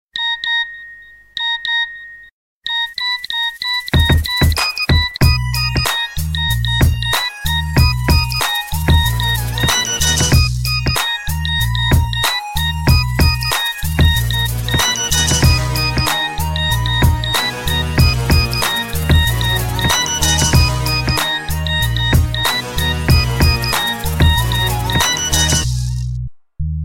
Hip Hop ringtone download